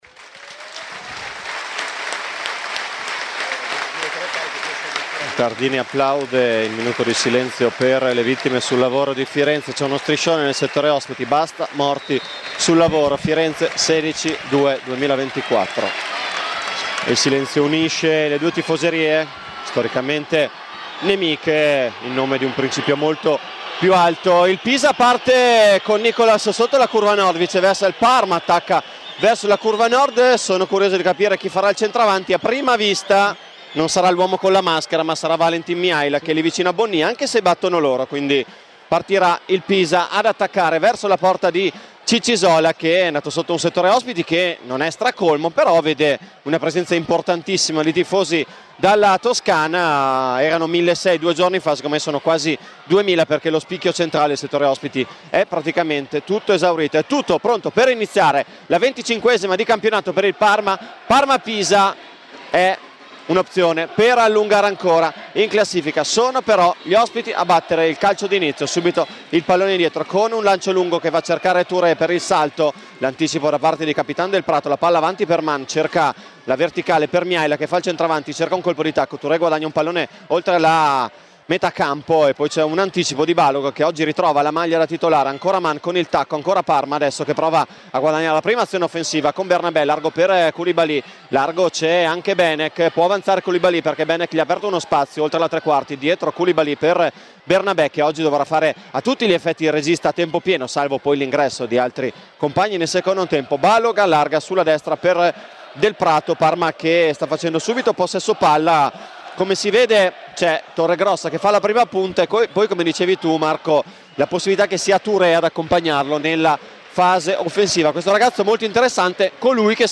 Il match inizia con un minuto di silenzio per ricordare le vittime del cantiere di Firenze.
Radiocronaca